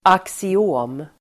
Ladda ner uttalet
Uttal: [aksi'å:m]